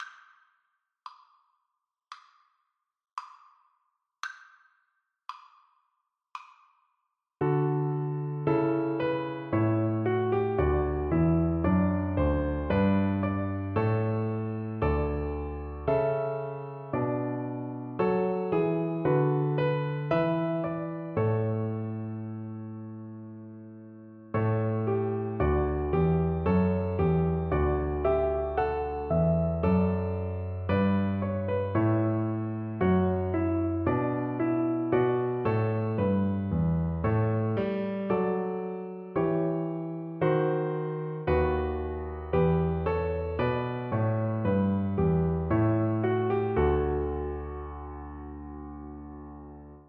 Cello
Traditional Music of unknown author.
D major (Sounding Pitch) (View more D major Music for Cello )
4/4 (View more 4/4 Music)
Christmas (View more Christmas Cello Music)